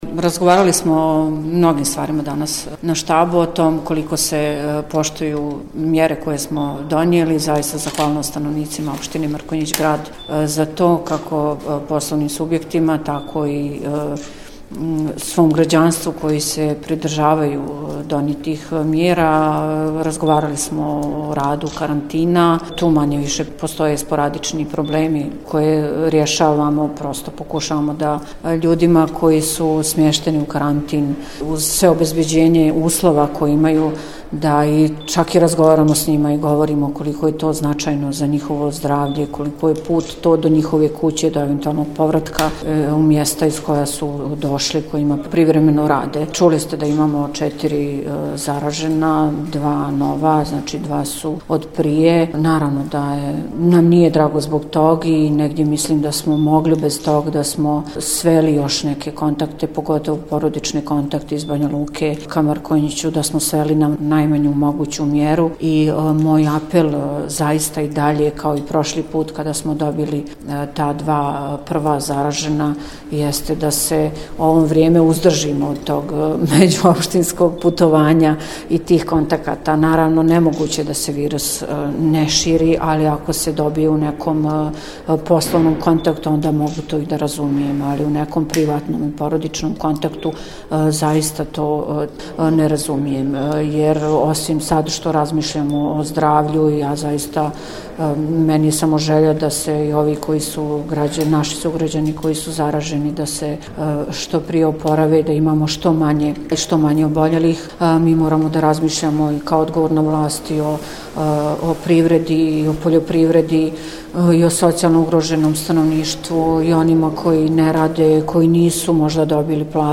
U nastavku poslušajte izjavu …